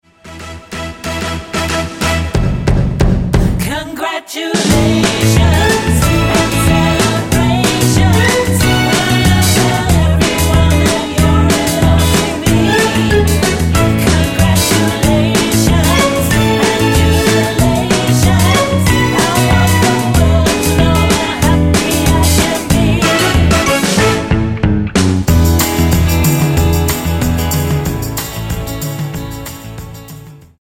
--> MP3 Demo abspielen...
Tonart:A-Bb mit Chor